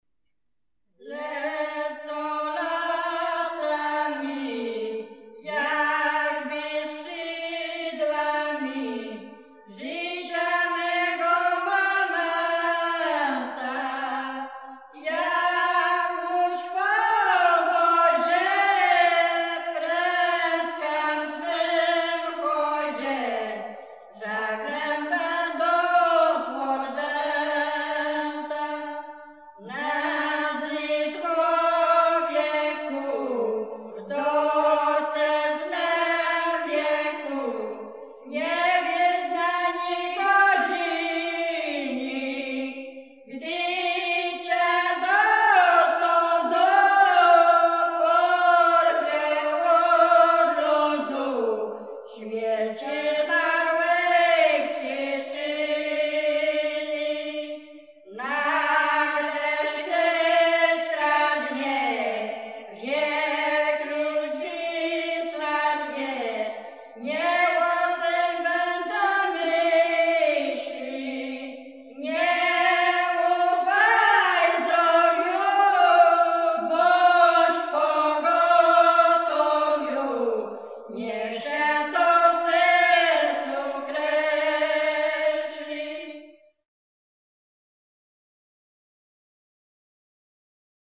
Zespół śpiewaczek z Bandyś
Kurpie
Pogrzebowa
pogrzebowe katolickie nabożne